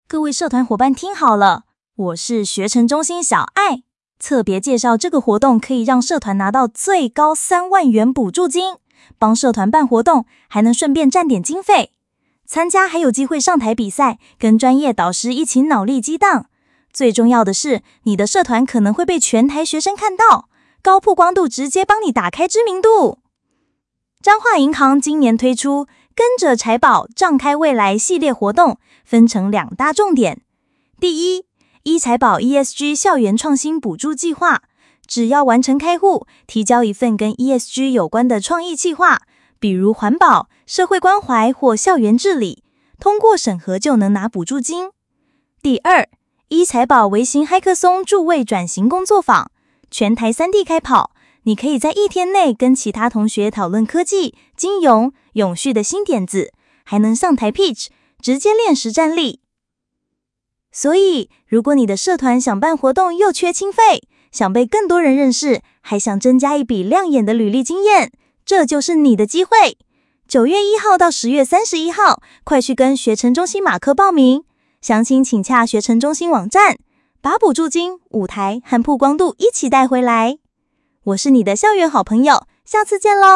學成中心虛擬主播小艾